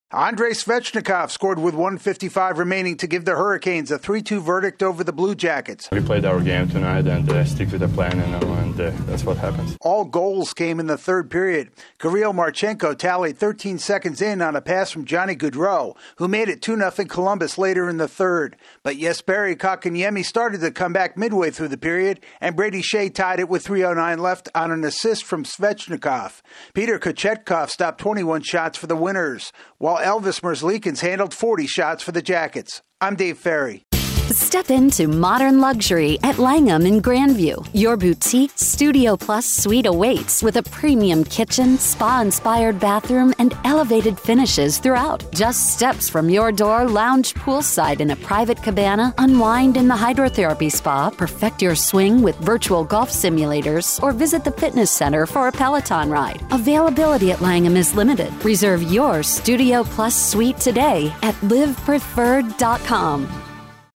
The Hurricanes score three straight in the final 10 minutes to beat the Blue Jackets. AP correspondent